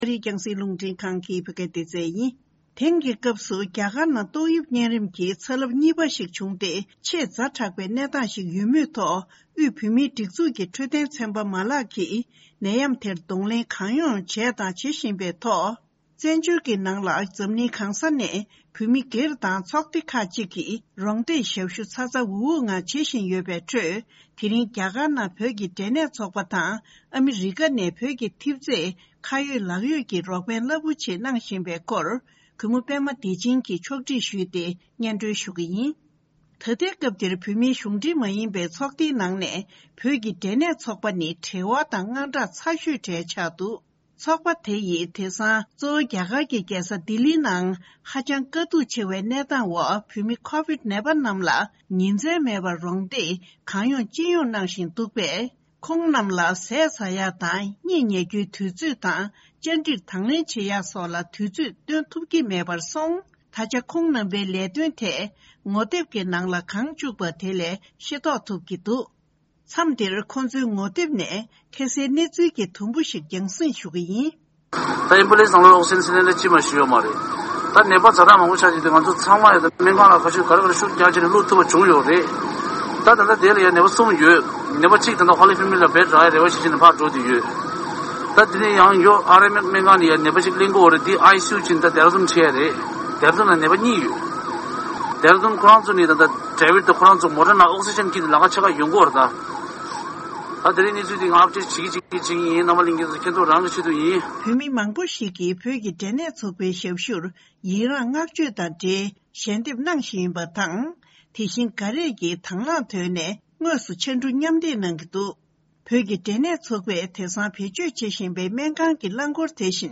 འབྲེལ་ཡོད་མི་སྣར་བཅར་འདྲི་ཞུས་ཏེ་ཕྱོགས་བསྒྲིགས་གནང་པ་ཞིག་གསན་རོགས་གནང་།།